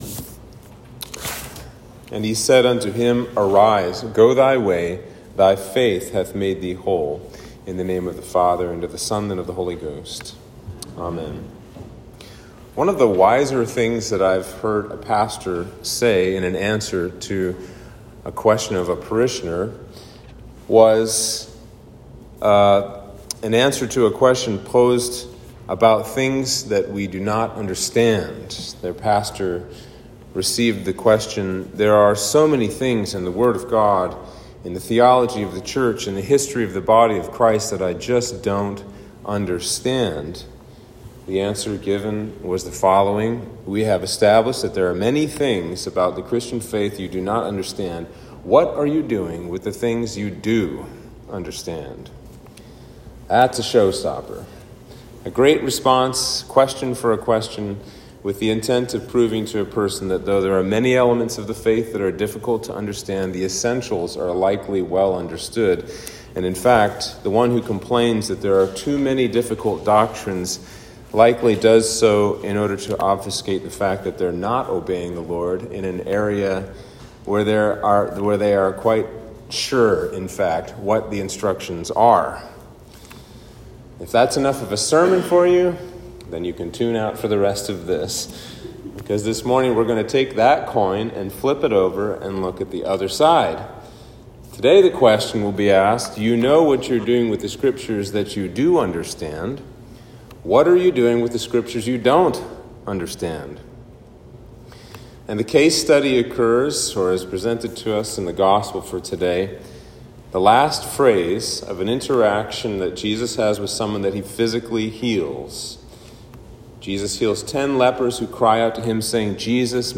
Sermon for Trinity 14